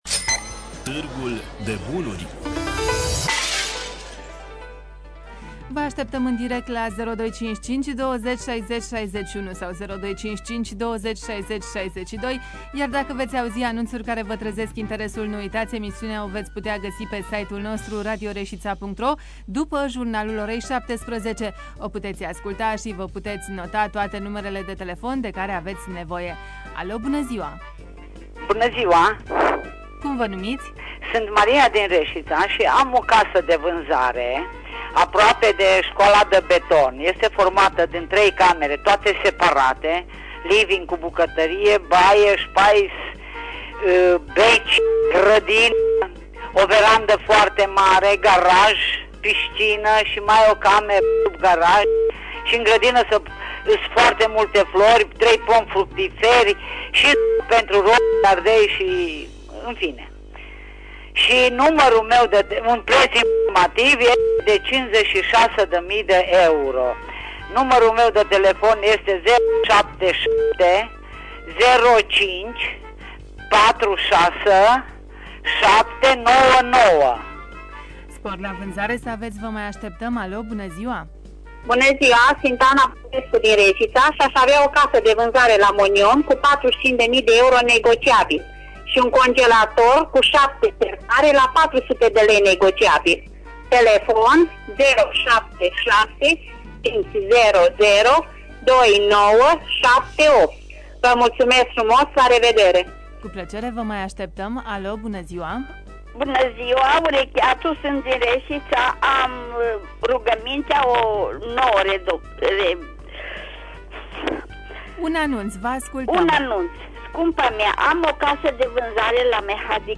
Înregistrarea emisiunii „Târgul de bunuri” de luni, 10.08.2015, difuzată la Radio România Reşiţa.